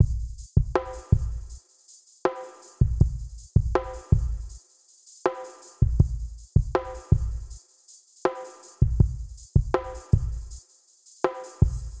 ロー、ミッド、ハイの棲み分けが綺麗に出ている音源です。ハイに行くほど音がはっきりして、低音のキックはフィルターががった加工がされています。
すべての音がCに集まっているのでNeblaやVectorが中央に張り付く反面、MagnitudeSpectrumはキック、スネア、ハイハットの各周波数帯にしっかり追従していますね。
spin_v2_Drum1.wav